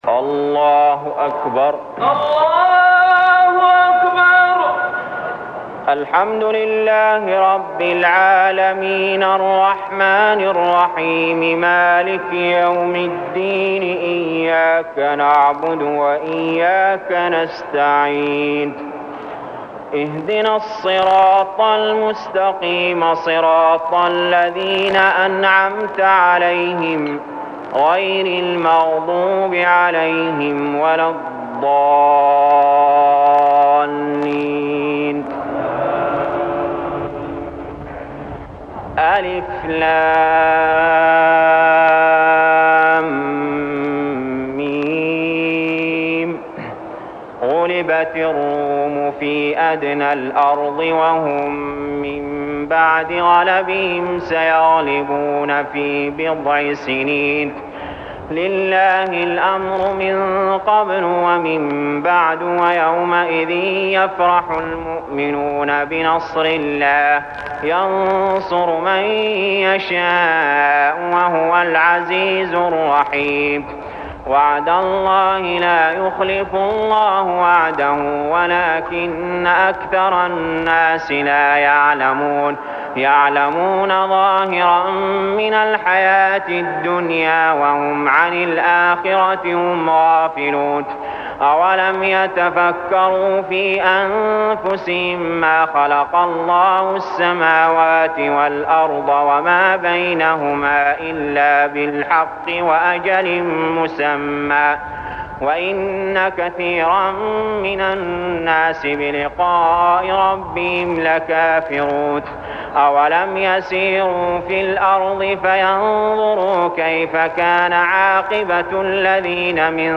المكان: المسجد الحرام الشيخ: علي جابر رحمه الله علي جابر رحمه الله الروم